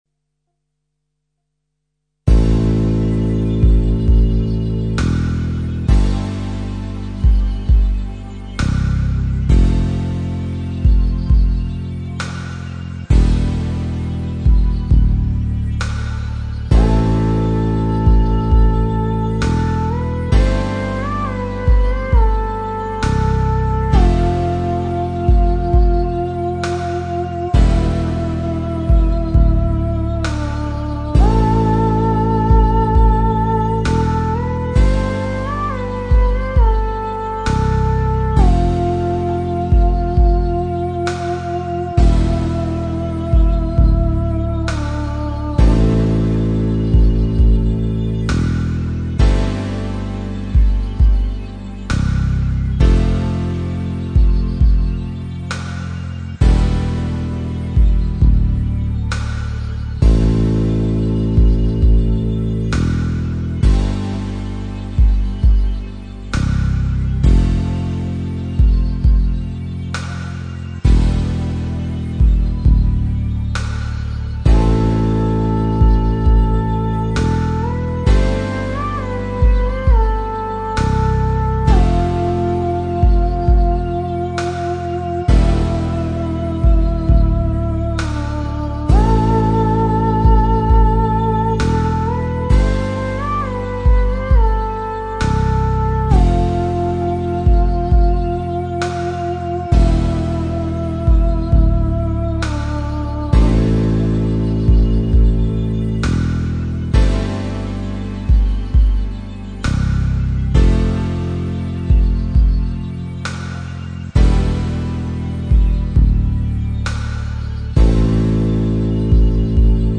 Musik zum Entspannen